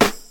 • 2000s Hip-Hop Steel Snare Drum Sound G Key 410.wav
Royality free steel snare drum sample tuned to the G note. Loudest frequency: 1865Hz